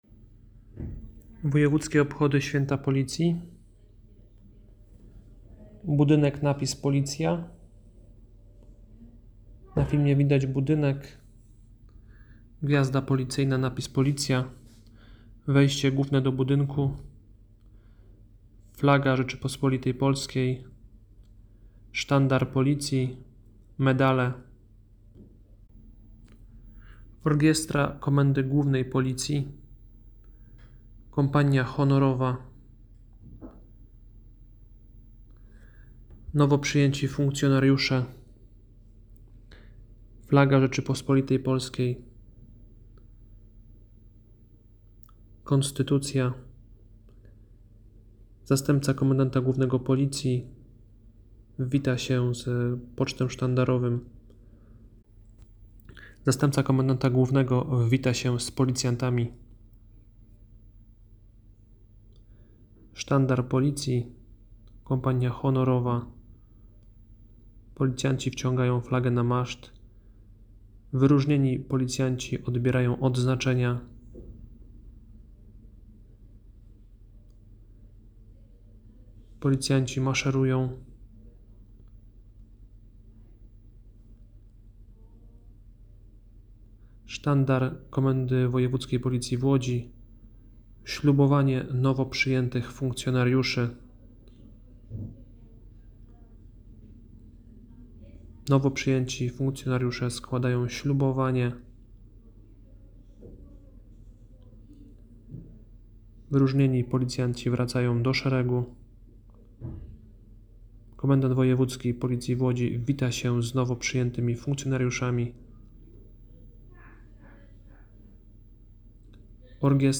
Wojewódzkie Obchody Święta Policji w Łodzi w 105. Rocznicę Powołania Policji Państwowej
Głos zabrał I Zastępca Komendanta Głównego Policji nadinsp. Roman Kuster.
Uroczysty Apel zwieńczyła defilada Kompanii Honorowej Łódzkiego Garnizonu Policji oraz  pododdziałów biorących udział w ceremonii,  przy akompaniamencie Orkiestry Reprezentacyjnej Policji, która odegrała ,, Warszawiankę”.